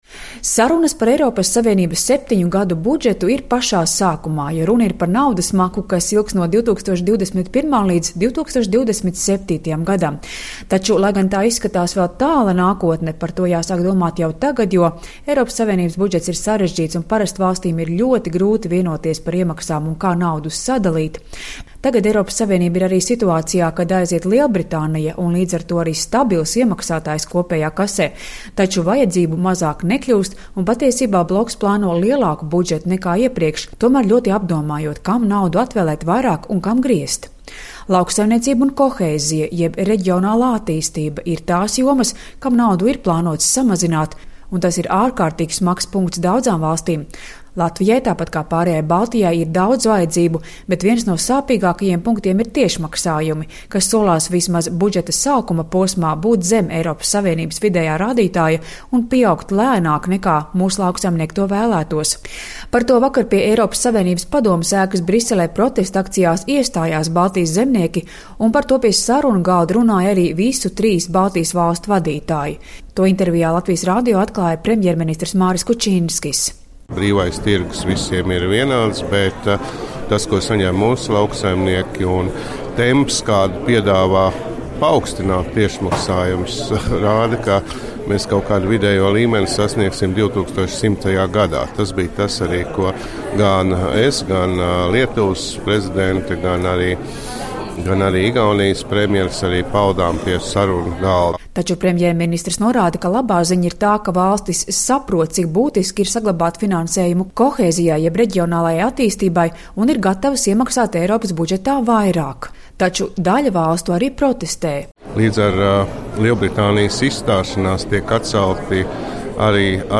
„Sarunas būs ļoti saspringtas un grūtas,” tā intervijā Latvijas Radio saka premjerministrs Māris Kučinskis, komentējot Eiropas Savienības septiņu gadu budžeta veidošanu un Baltijas valstu prasības piešķirt taisnīgākus tiešmaksājumus mūsu zemniekiem. Taču premjerministrs norāda, ka labā ziņa ir tā, ka valstis saprot, cik būtiski ir saglabāt finansējumu reģionālajai attīstībai un ir gatavas iemaksāt Eiropas budžetā vairāk.